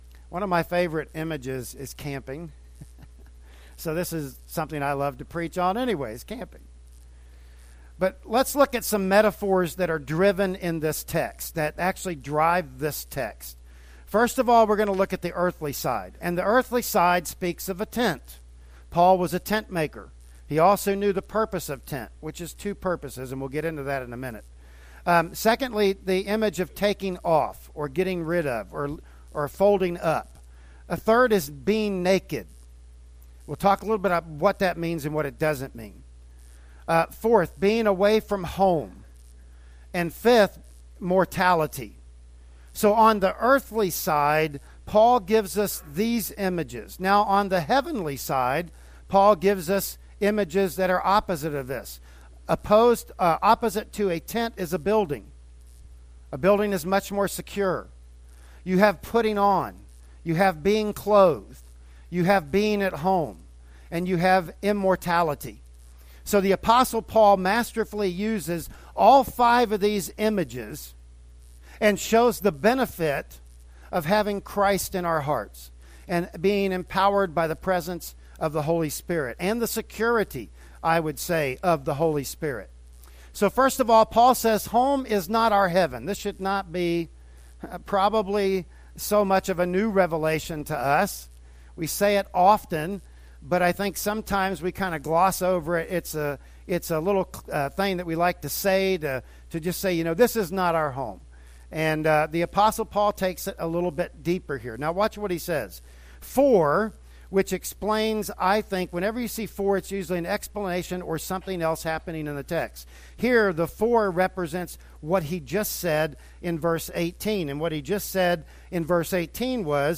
"2 Corinthians 5:1-5" Service Type: Sunday Morning Worship Service Bible Text